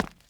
Sounds / sfx / Footsteps / Concrete
Concrete-06.wav